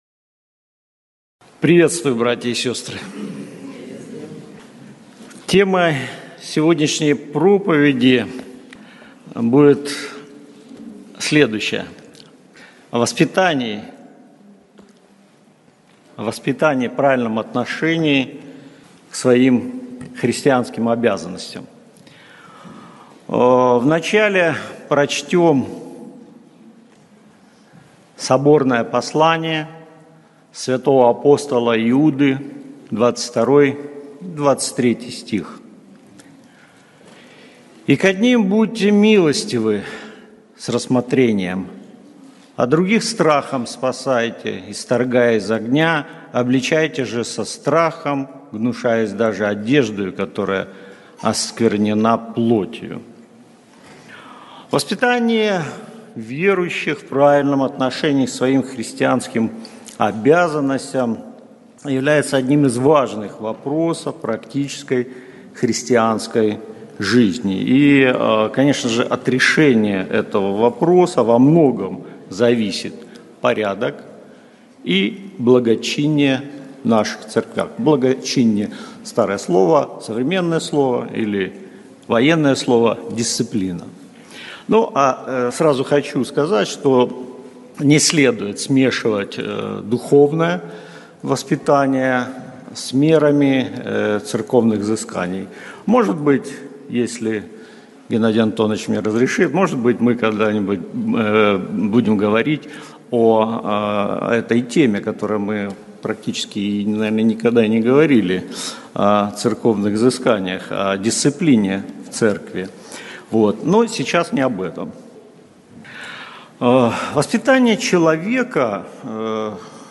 Церковь ЕХБ г.Слуцк